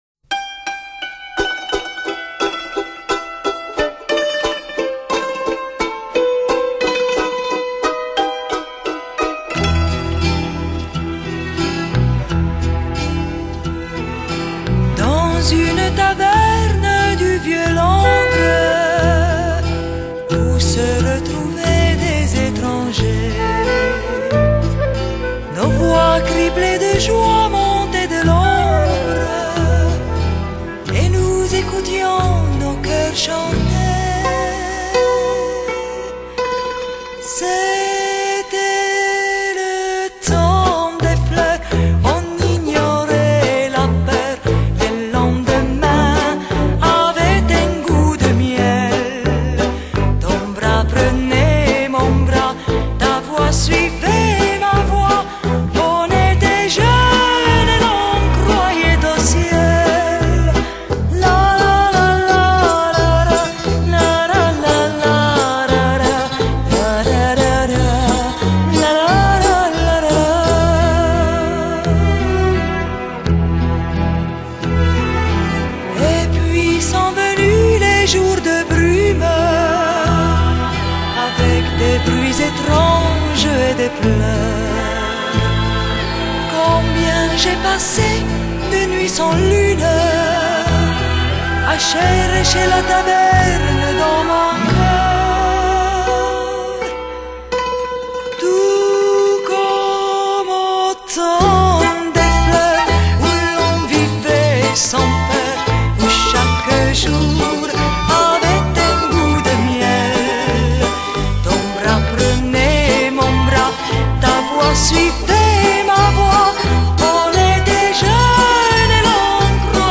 MP3 320 Kbps CBR | Retro | 139,60 MB